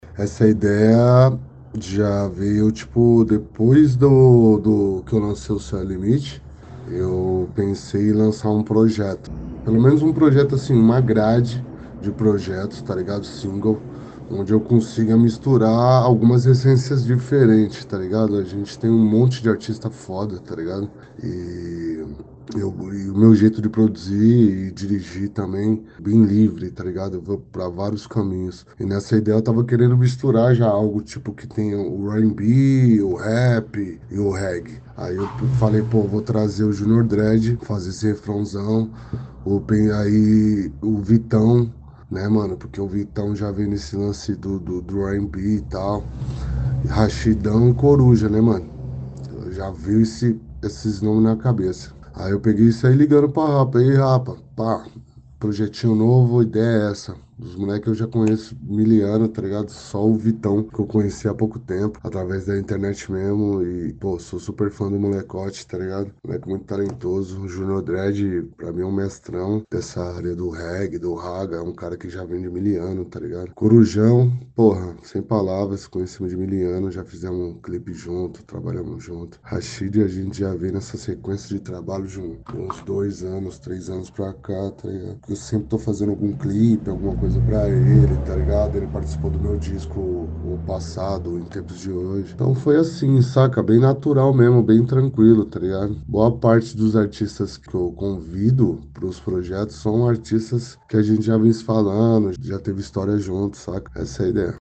Black Pipe Entrevista